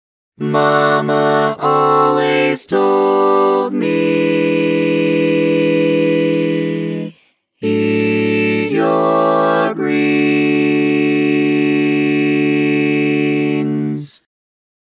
Key written in: D Major
How many parts: 4
Type: SATB
All Parts mix: